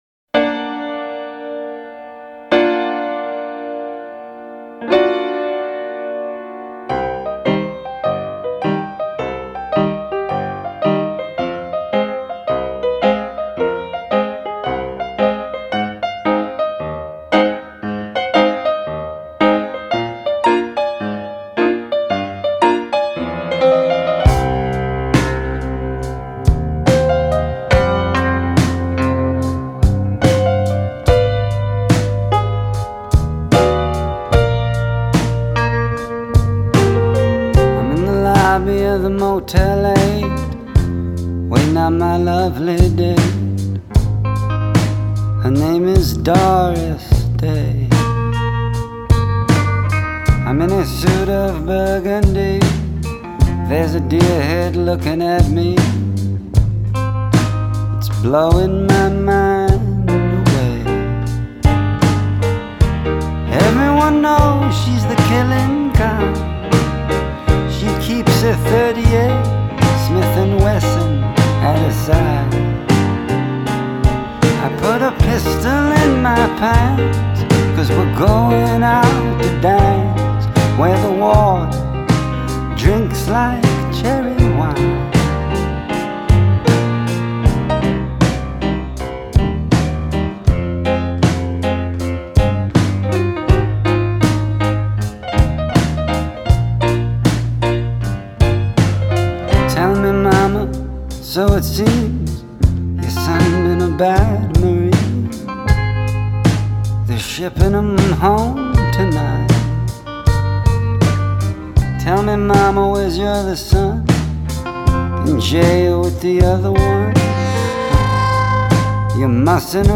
jaunty New Orleans infused melody